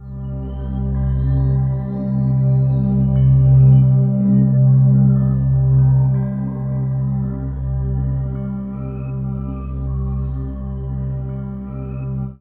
36aj01pad1cM.wav